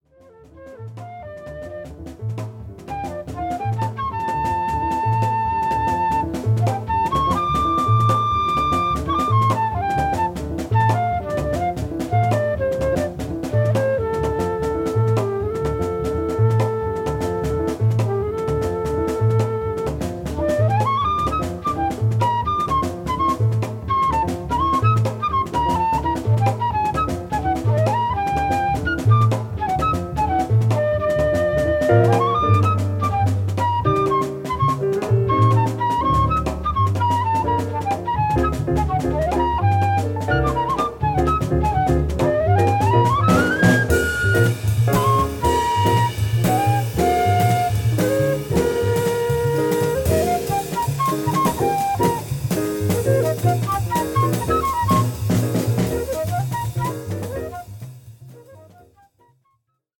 ハード・バップ〜モード・ジャズ・ファンにも人気の1枚です。